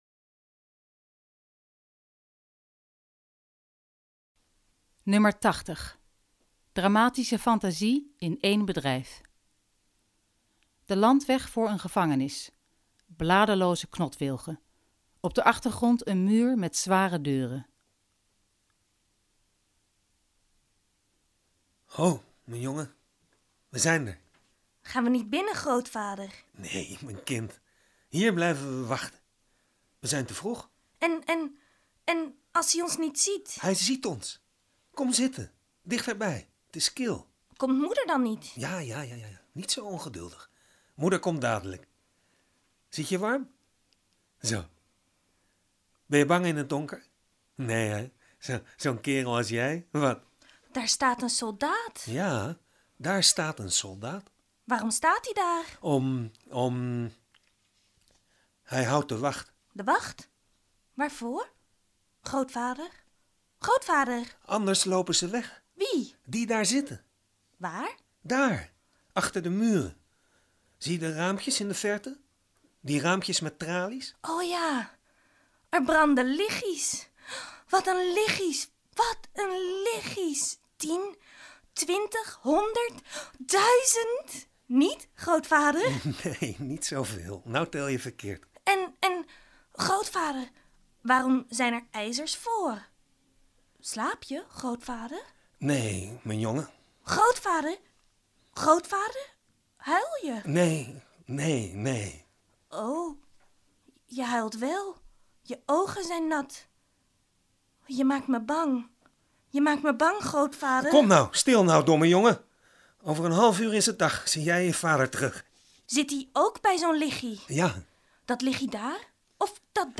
Dramatische fantasie in één bedrijf